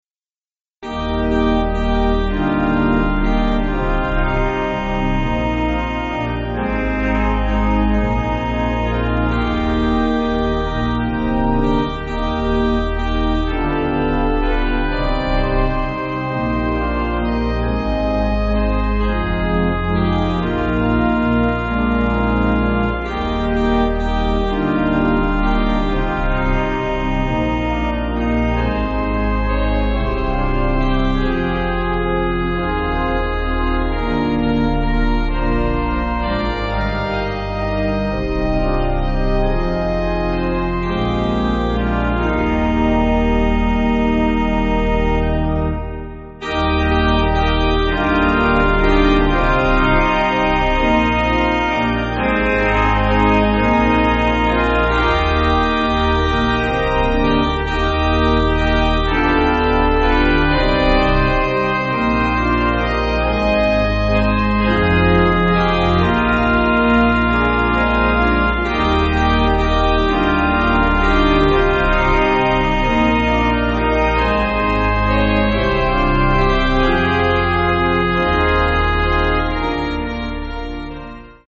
Organ
(CM)   2/Eb
With Melody